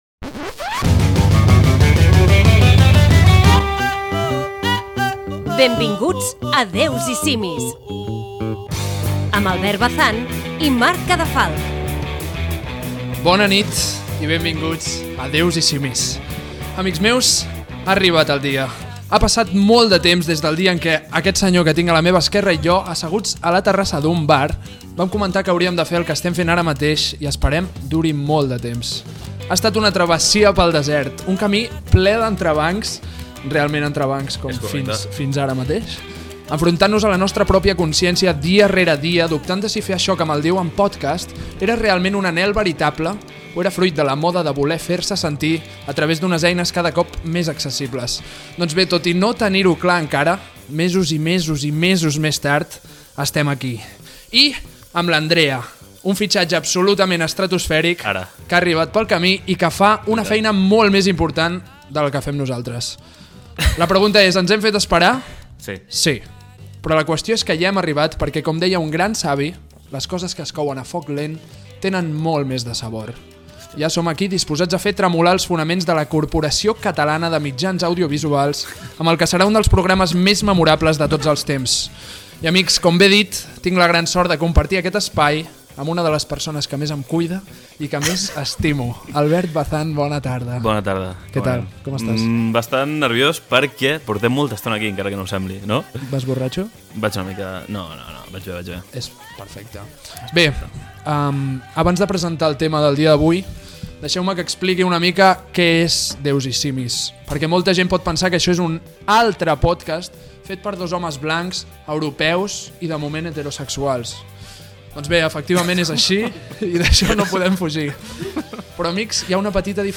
Careta del programa
indicatiu del programa i conversa amb l'invitat Gènere radiofònic Entreteniment